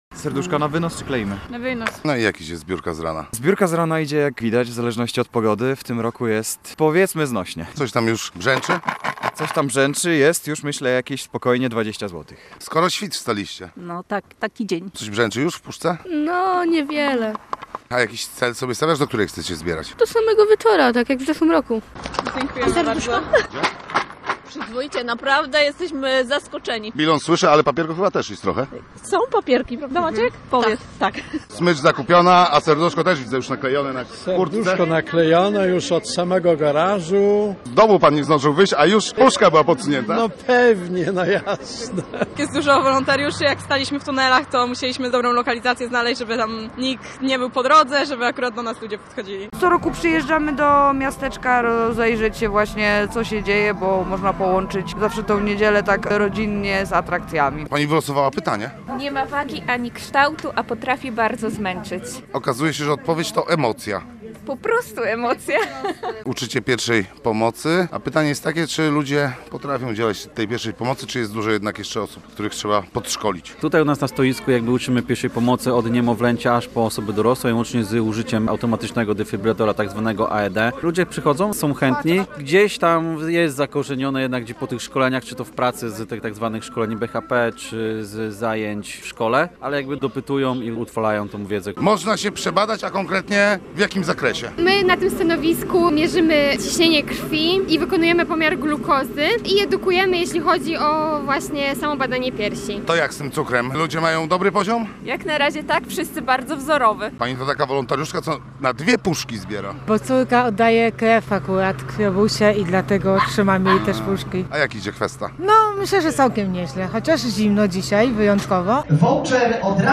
Posłuchaj, jak przebiegała niedzielna kwesta na Pomorzu i jak bawiono się w orkiestrowym miasteczku w Gdańsku: